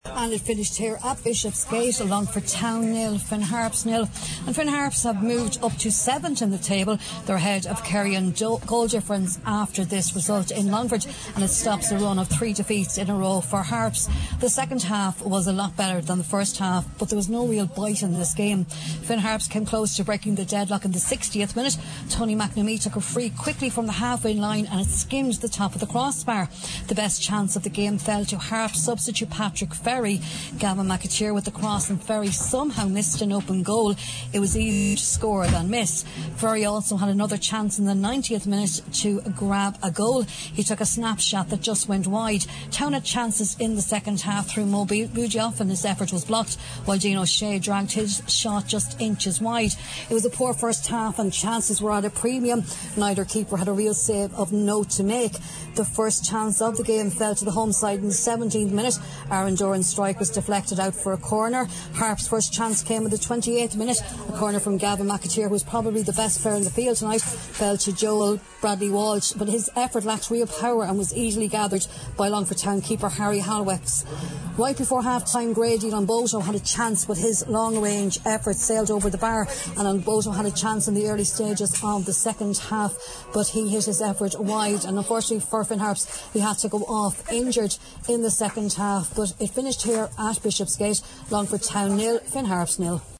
from Bishopsgate: